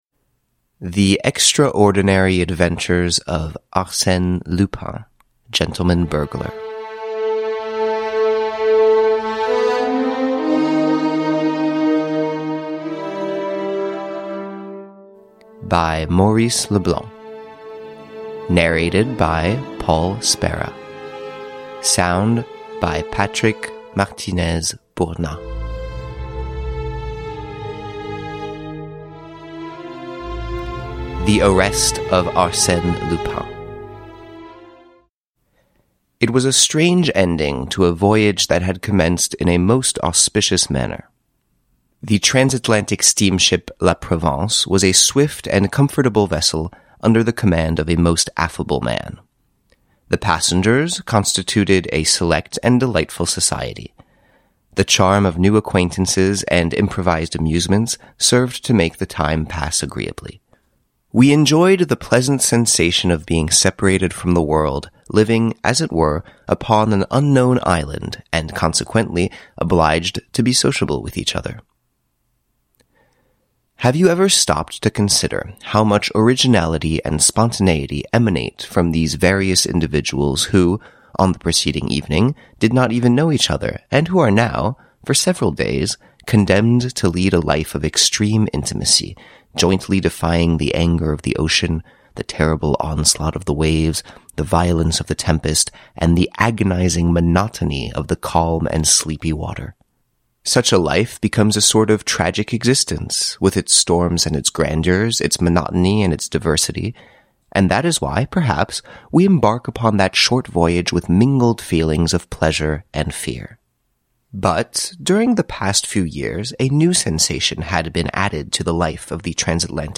Arsène Lupin, Gentleman-Thief: 10 Stories – Ljudbok – Laddas ner